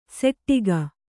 ♪ seṭṭiga